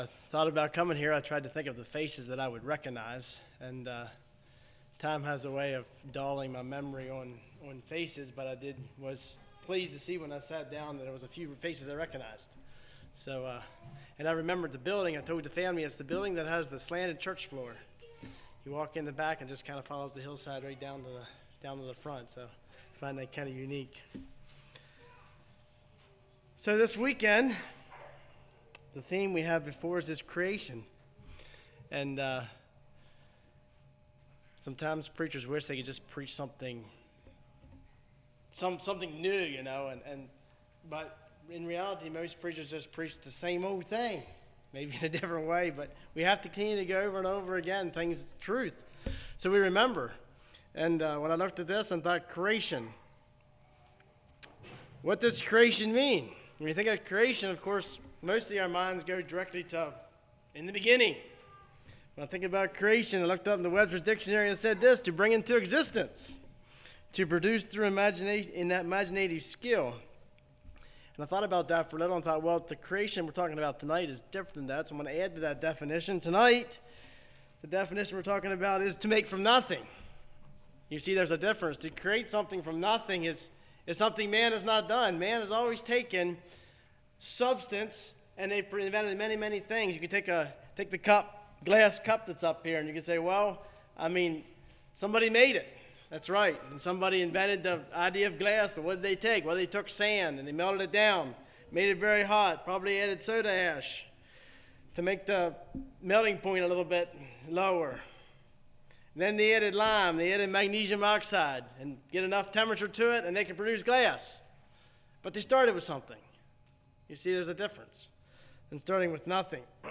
Sermons
Susquehanna Valley | All Day Meetings 2025